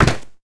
Foley Sports / Football - Rugby / Pads Hit Clash.wav
Pads Hit Clash.wav